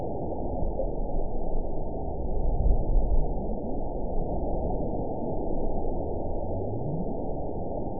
event 918286 date 10/30/23 time 04:37:38 GMT (2 years, 1 month ago) score 9.50 location TSS-AB04 detected by nrw target species NRW annotations +NRW Spectrogram: Frequency (kHz) vs. Time (s) audio not available .wav